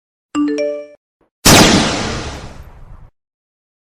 Nada dering suara Bom Meledak
Keterangan: Download suara ledakan bom mp3 sebagai nada dering untuk WA Anda.
nada-dering-suara-bom-meledak-id-www_tiengdong_com.mp3